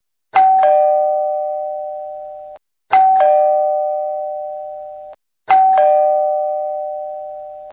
campainha.mp3